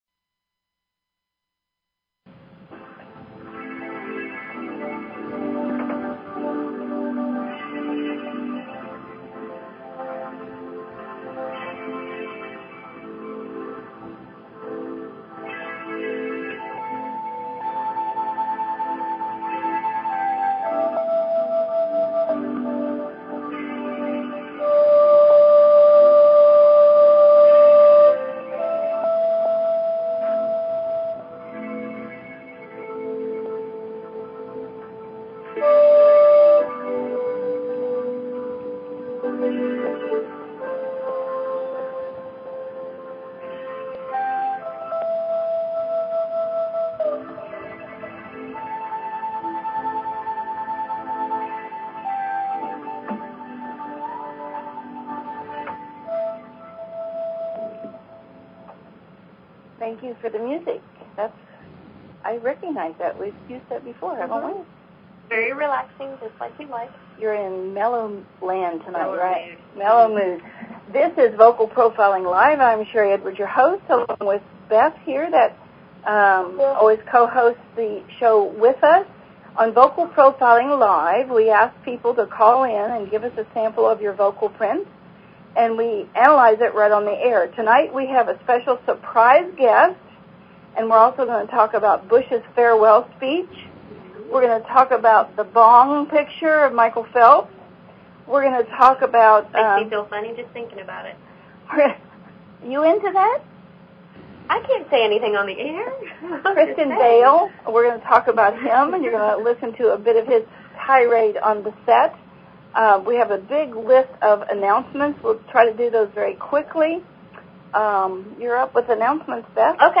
Talk Show Episode, Audio Podcast, Vocal_Profiling_Live and Courtesy of BBS Radio on , show guests , about , categorized as
We even played his f..k..g-filled tirade for the audience.